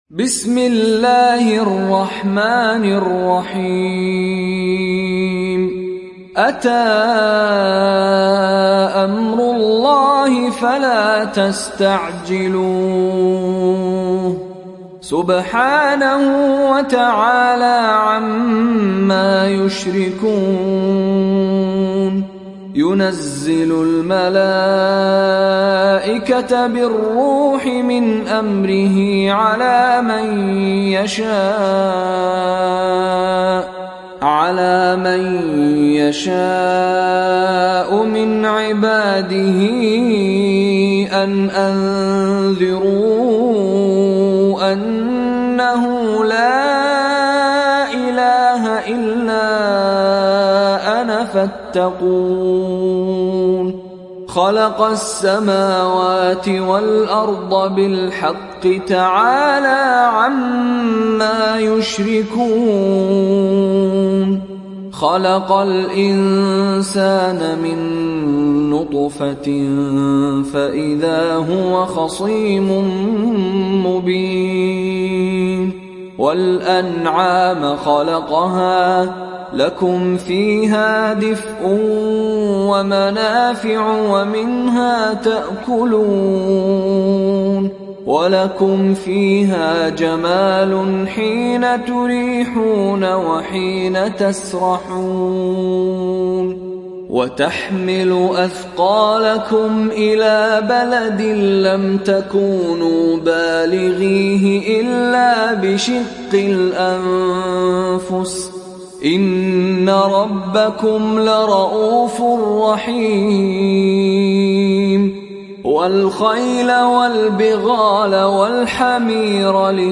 Surat An Nahl Download mp3 Mishary Rashid Alafasy Riwayat Hafs dari Asim, Download Quran dan mendengarkan mp3 tautan langsung penuh